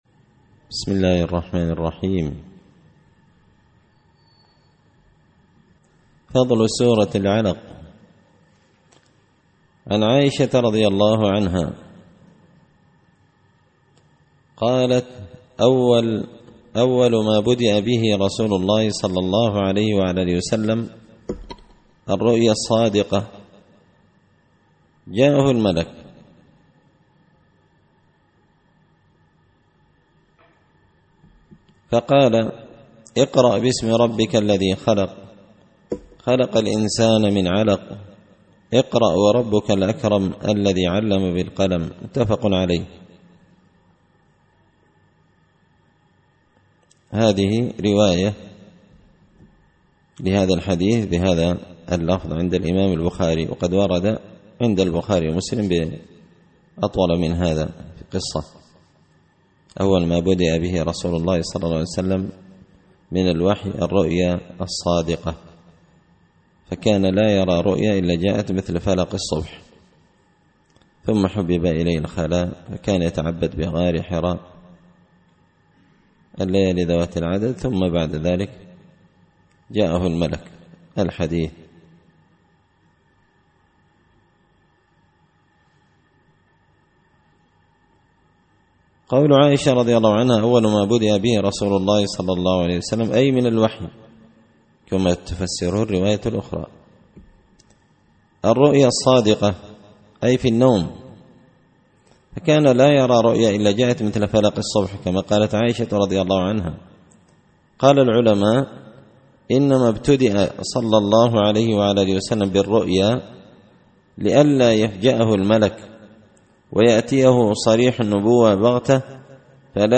الأحاديث الحسان فيما صح من فضائل سور القرآن ـ الدرس الثامن والأربعون
دار الحديث بمسجد الفرقان ـ قشن ـ المهرة ـ اليمن